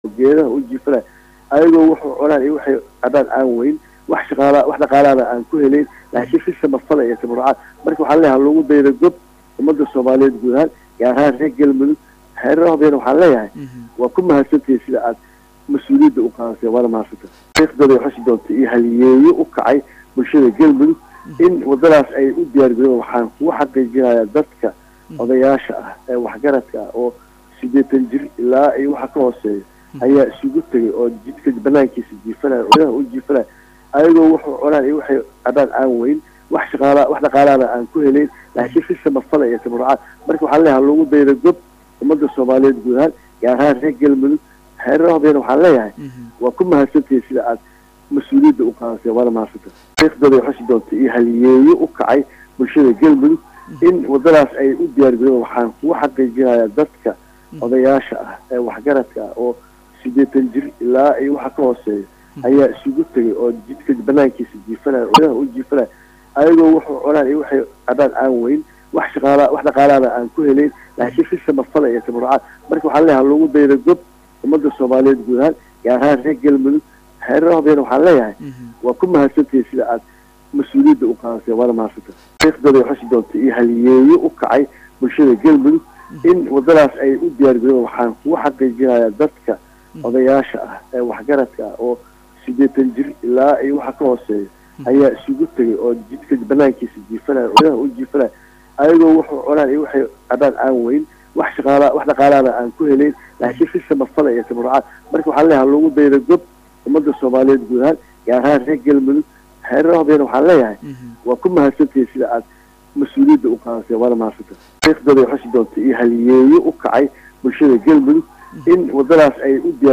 Guddoomiyaha Degmada Hobyo ee Maamulka Galmudug C/llaahi Axmed Cali Faad oo la hadlay Saaka Barnaamijka Subax wanaagsan ee ka baxa
C/llaahi Axmed Cali Faad Guddoomiyaha Degmada Hobyo ee Maamulka Galmudug wareysi uu siiyay Radio Muqdisho Codka Jamhuuriyadda Federaalka Soomaaliya ayuu ku sheegay in waftigaan ka socda Dowladda dhexe iyo Galmudug ay ka qeyb gali doonaan Manuusabada soo gaba gabaynta dhismaha wadada isku xirta Magaalooyinka Gaalkacyo iyo Hobyo.